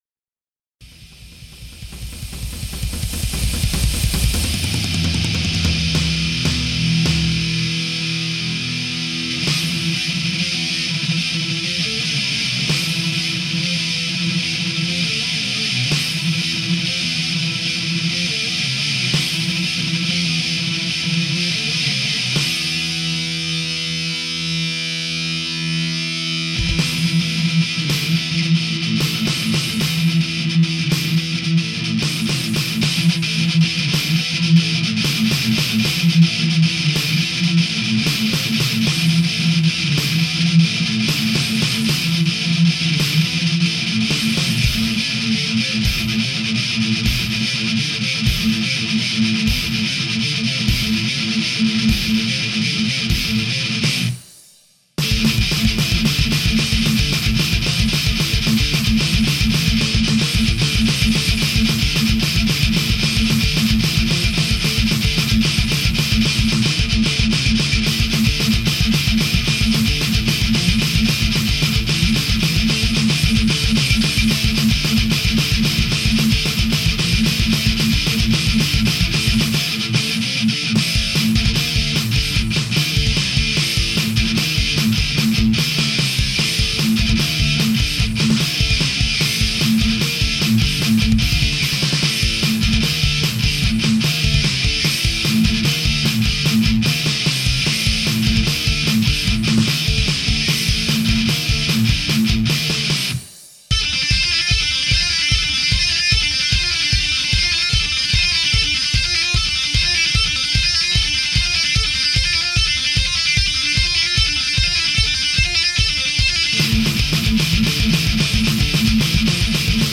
Metal!